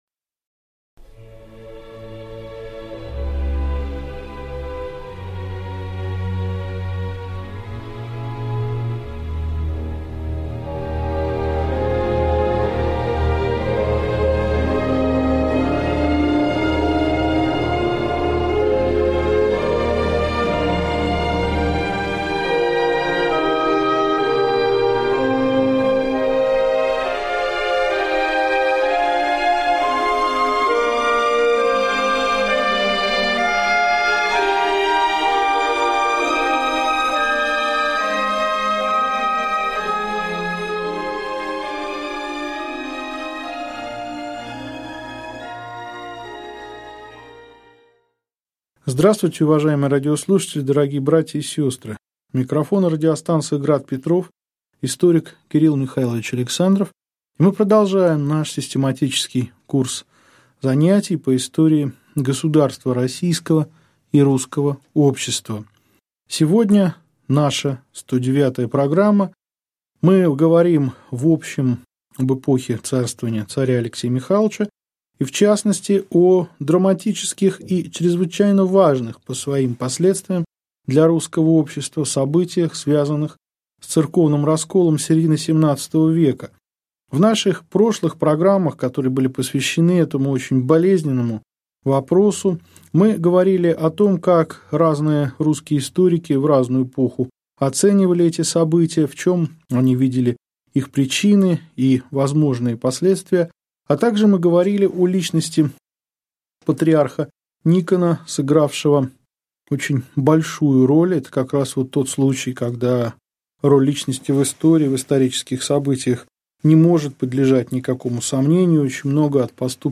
Аудиокнига Лекция 109. Конфликт патриарха Никона с царем